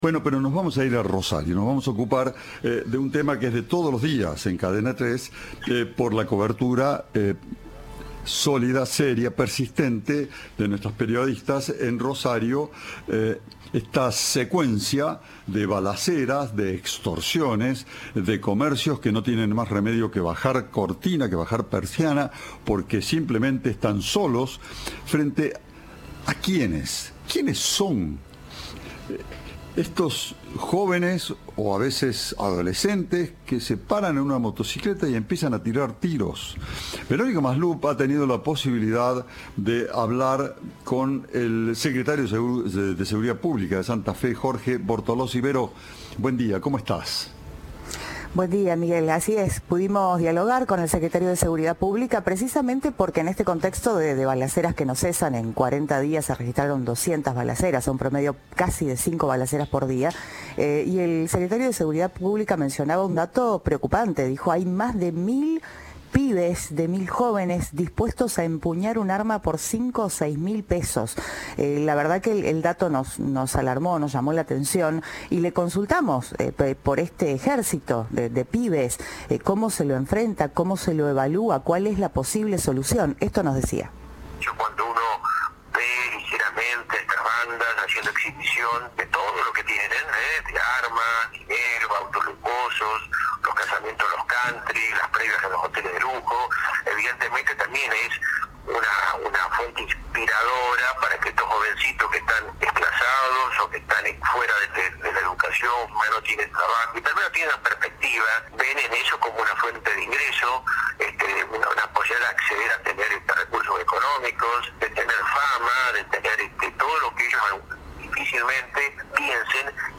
Lo indicó el secretario de Seguridad Pública de Santa Fe, Jorge Bortolozzi.